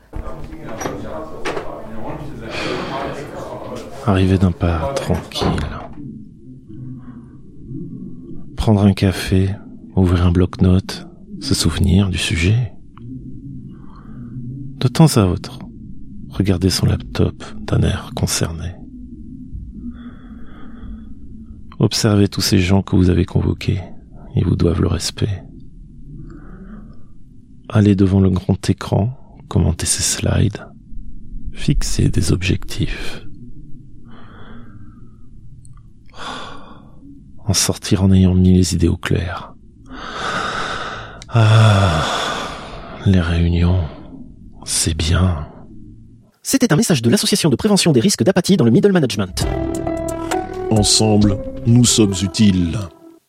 Extrait de l'émission CPU release Ex0227 : lost + found (un quart null).
[bruit d'une porte ouverte puis fermée. bruits étouffés de téléphones Cisco, de conversations]
[bruit d'une porte ouverte puis fermée. bruits étouffés de téléphones Cisco, de conversations] [voix très apaisante, limite ASMR] Arriver d'un pas tranquille.
[jingle République Française modifié] Ensemble, nous sommes utiles.